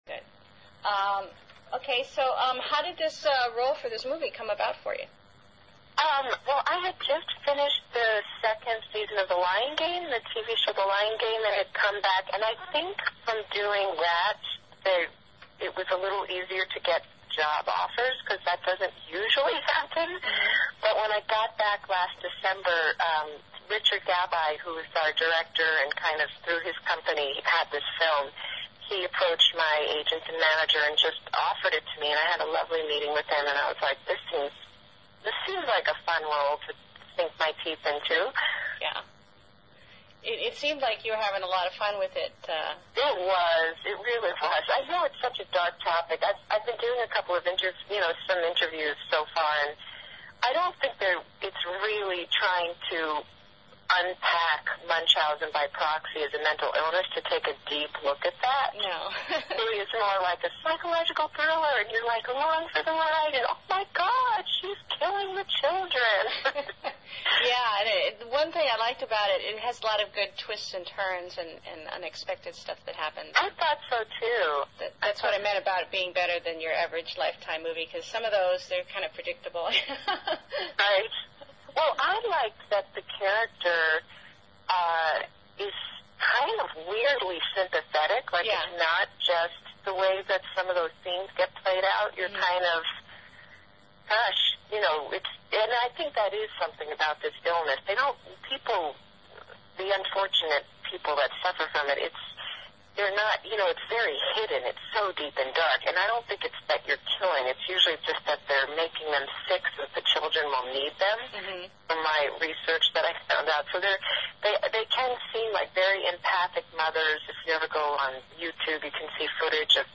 Interview with Helen Slater of "The Good Mother"
She was very lovely on the phone - a real person, not some fake Hollywood type.